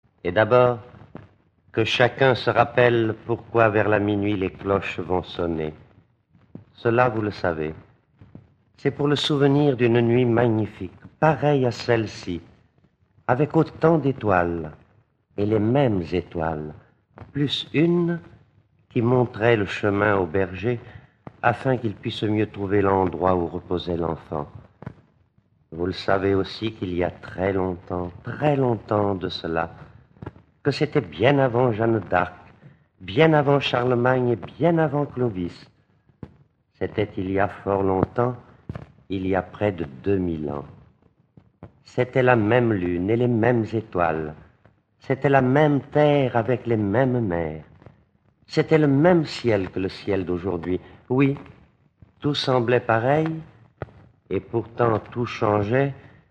Ce conte musical ravira les enfants, avec une très belle histoire mettant en scène le Père Noël. Raconté par Pierre Larquey et Raymond Asso
Enregistrement original de 1956 (extraits)